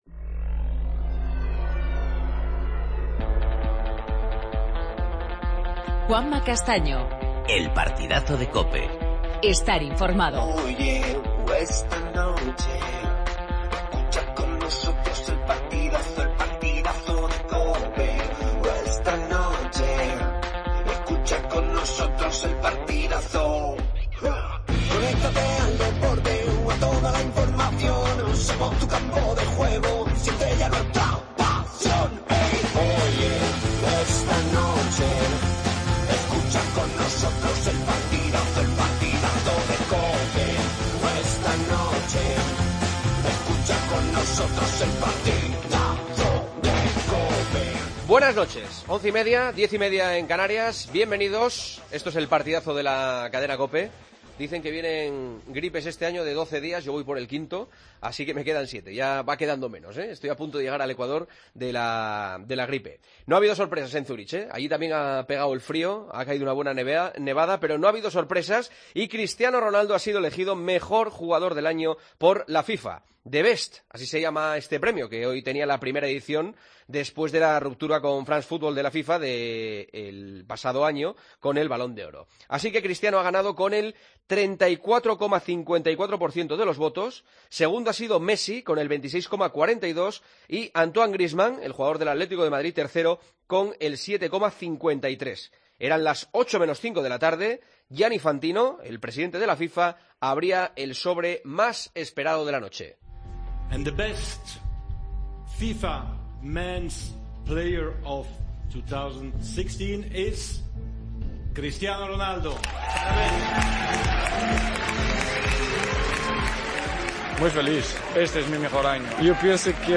Hablamos con Cristiano Ronaldo, tras ser galardonado con el Premio 'The Best' como mejor jugador del mundo en 2016....